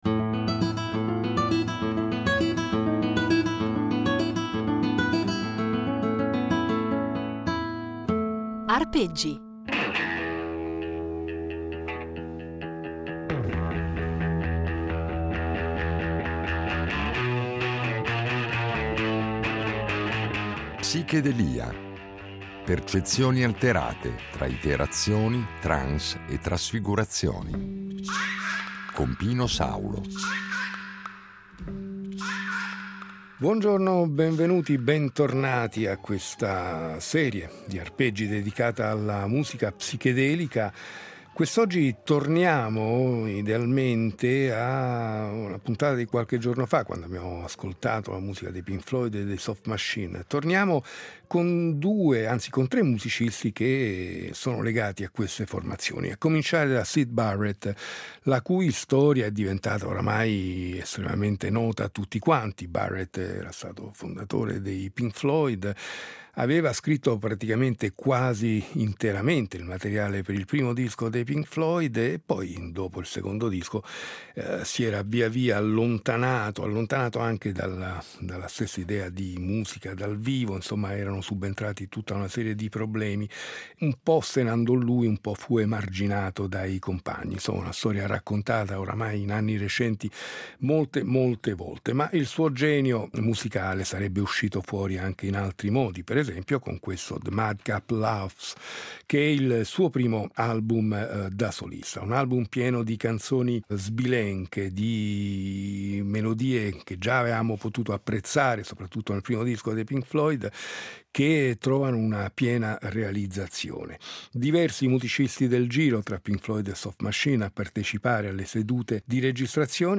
Termine volutamente ambiguo, che va a indicare un insieme di musiche nate intorno alla metà degli anni ‘60 aventi in comune un riferimento alle droghe allucinogene, ma nelle quali la musica incorpora elementi provenienti da altre culture musicali - dall’oriente soprattutto, con l’utilizzo di strumenti come il sitar o le tabla - effetti sonori, tecniche di registrazione che pongono in qualche modo elevano lo studio di registrazione al rango di uno strumento al pari degli altri, con l’uso massiccio di loop, nastri al contrario, riverbero, e ancora elementi del jazz modale e più sperimentale, droni, il tutto condito da luci stroboscopiche e testi surreali o con riferimenti più o meno velati a esperienze allucinatorie.